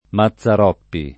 [ ma ZZ ar 0 ppi ]